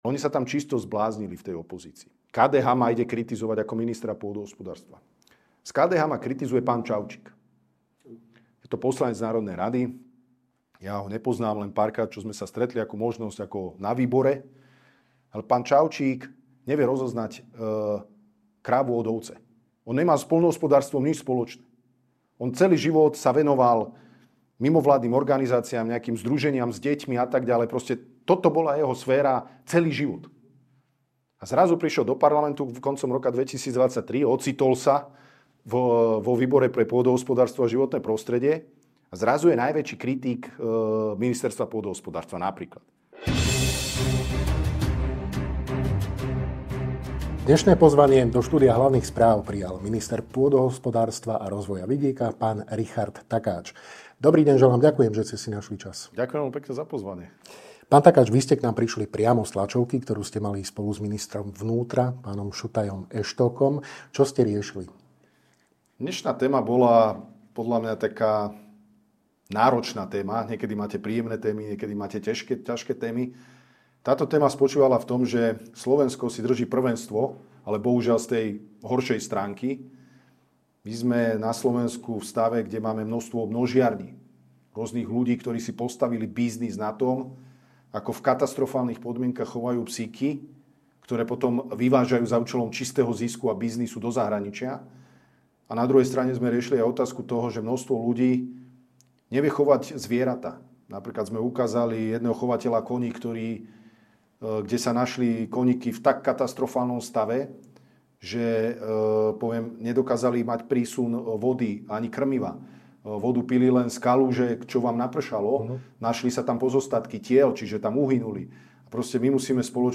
O týchto, aj mnohých iných témach, sme sa rozprávali s ministrom pôdohospodárstva a rozvoja vidieka SR, Ing. Richardom Takáčom.